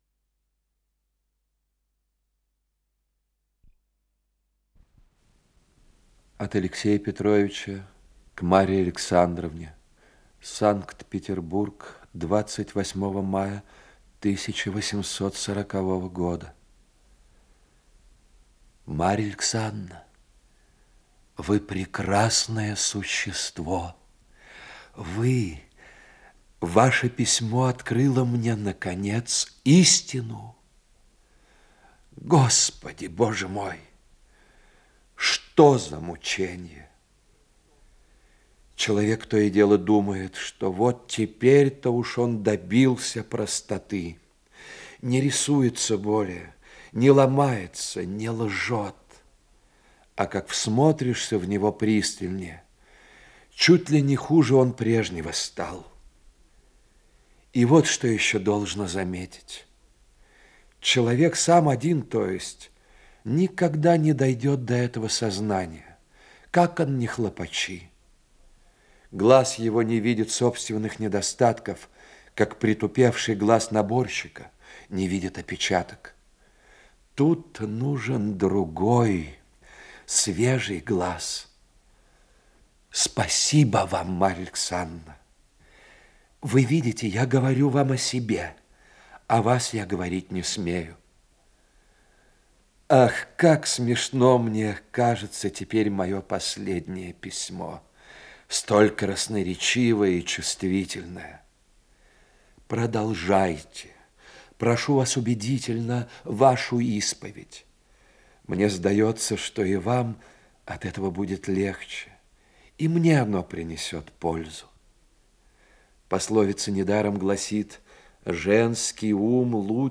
Исполнитель: Лидия Толмачева и Геннадий Бортников - чтение
Повесть, передача 2-я, 1 часть